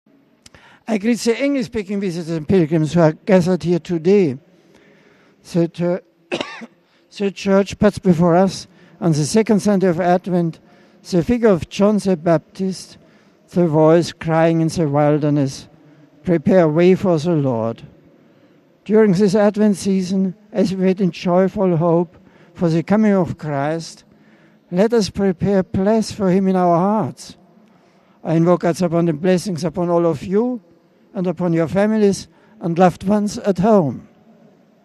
Pope Benedict also greeted English-speaking pilgrims present at Sunday’s ‘Angelus’.